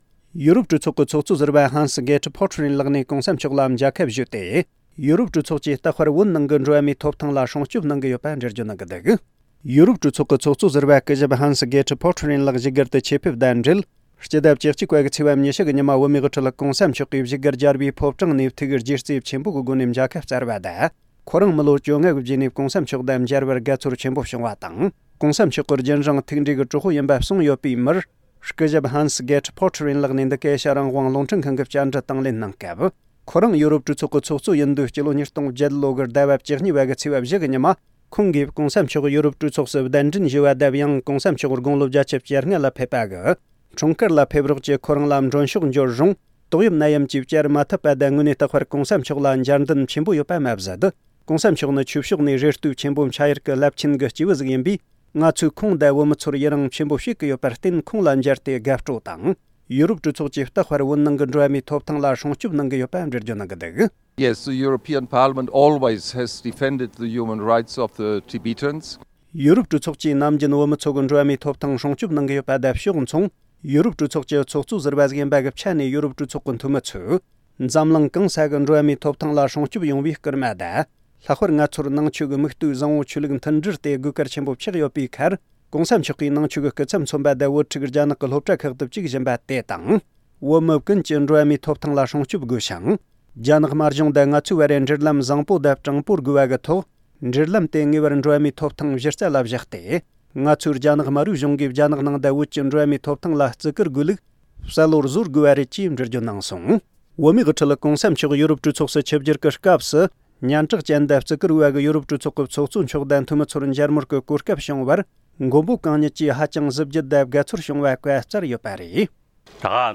སྐུ་ཞབས་ཧན་སི་གྷེར་ཊི་ཕོ་ཊི་རེན་ལགས་ནས་འདི་ག་ཨེ་ཤེ་ཡ་རང་དབང་རླུང་འཕྲིན་ཁང་གི་བཅར་འདྲི་དང་ལེན་སྐབས།